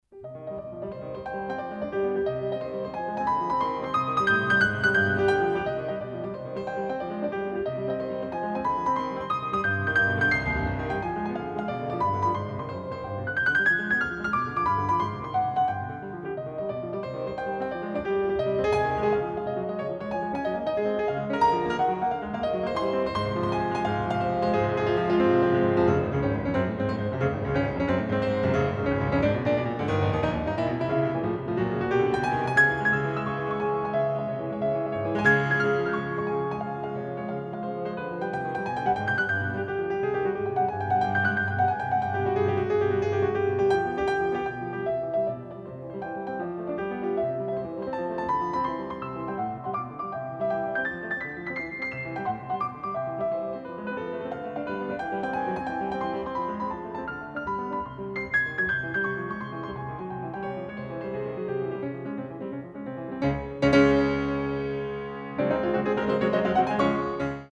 Allegro 8'59